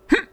jump (3).wav